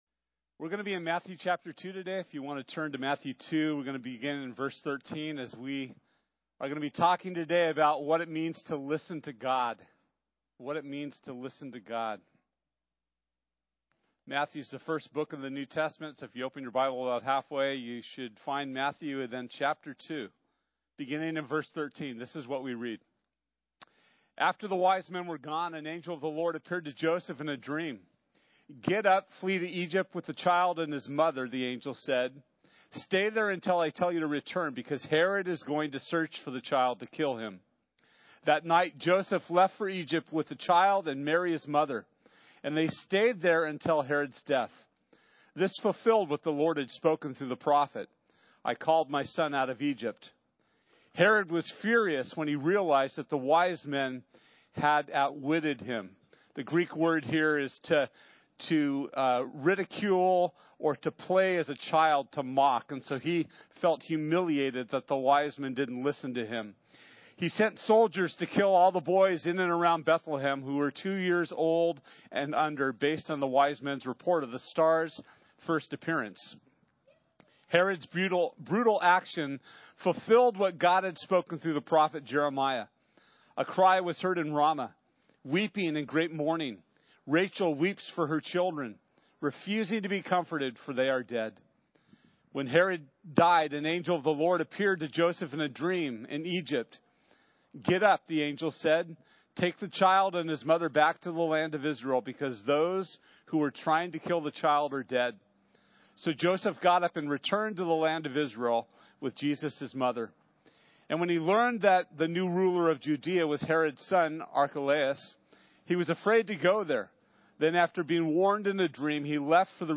Matthew 2:13-23 Service Type: Sunday This week our teaching will be from Matthew 2:13-23.